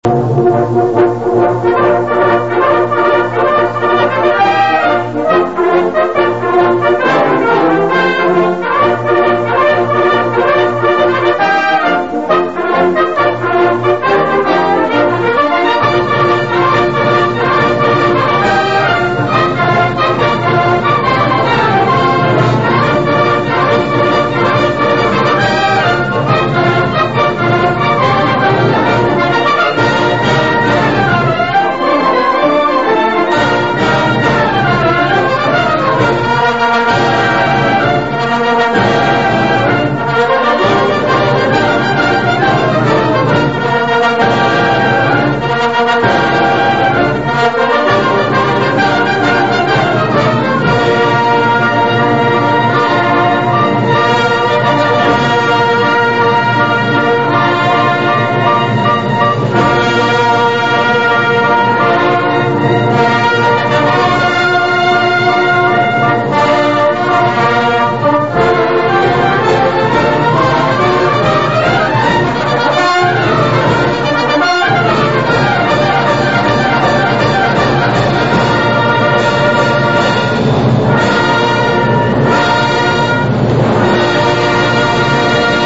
Ascolta Registrazione dal vivo anni 80 Teatro Oriente - Torre del Greco Se non si ascolta subito la musica attendere qualche secondo solo la prima volta.